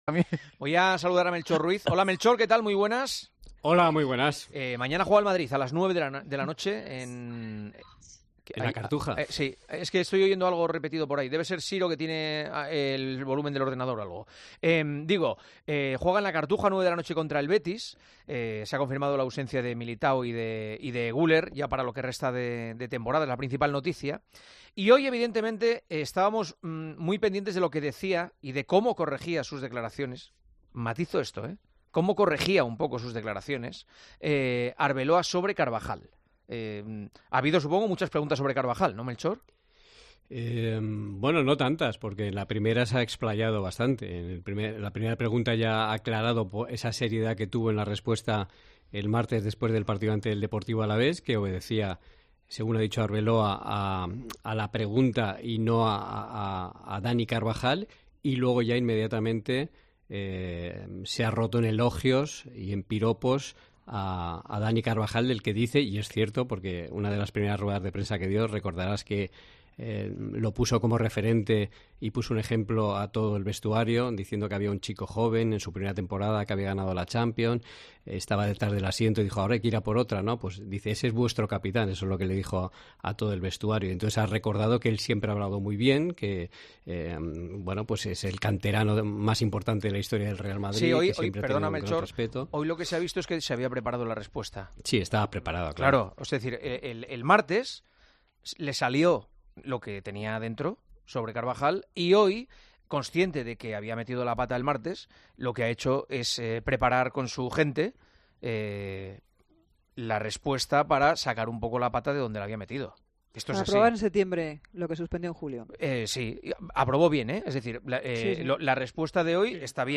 Juanma Castaño analiza con los tertulianos de El Partidazo de COPE la rueda de prensa de Arbeloa sobre Carvajal